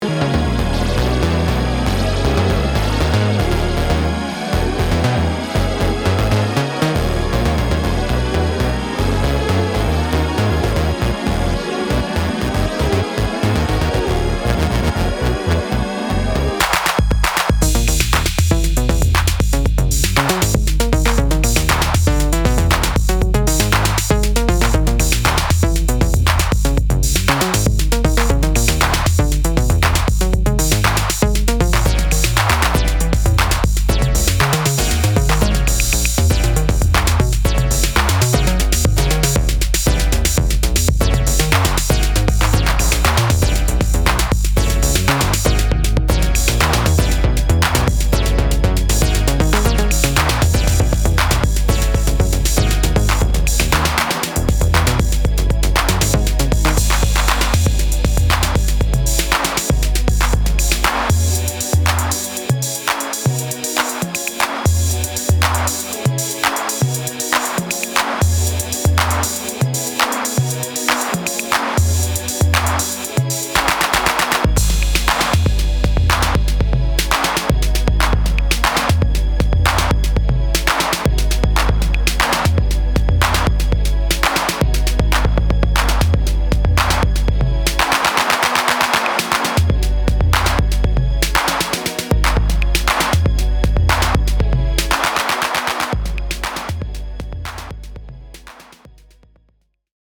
エレクトロニックなジャズファンク・ハウスを展開！
ジャンル(スタイル) HOUSE / JAZZ FUNK